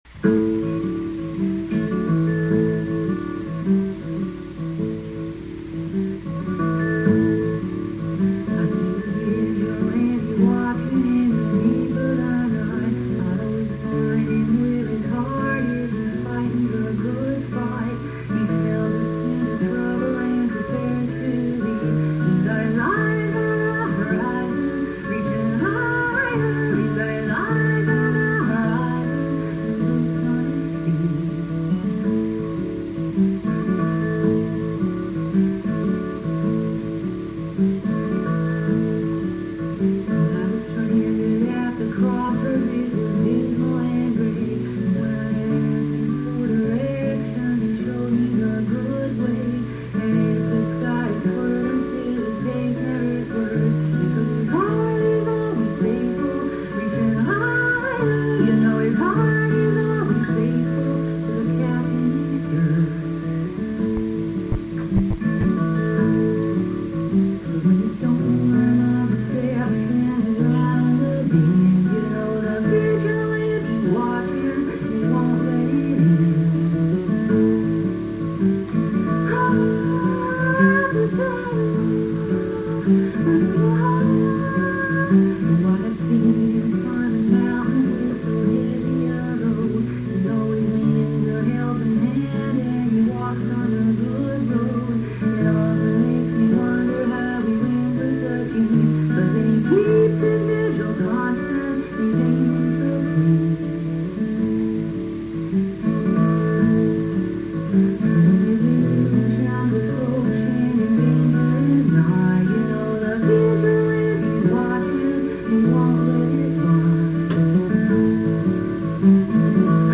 The audio quality of these ranges from poor to very good.